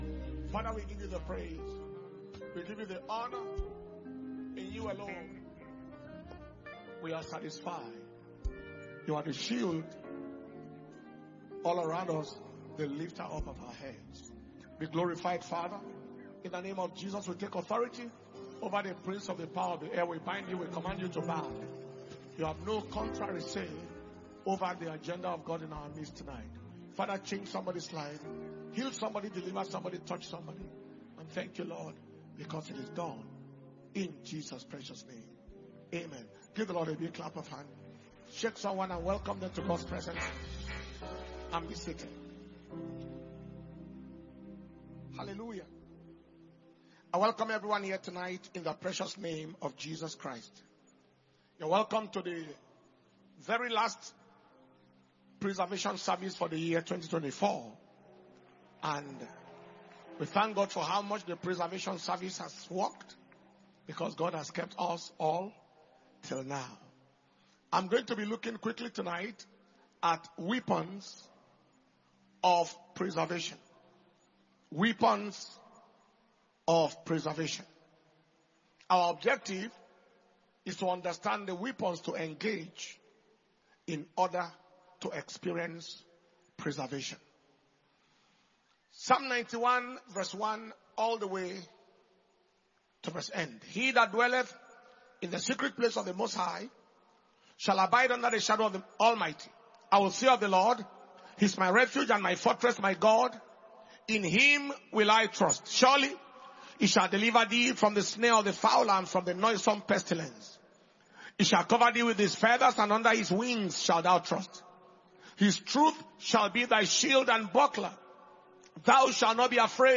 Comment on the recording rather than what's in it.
2024 December Preservation And Power Communion Service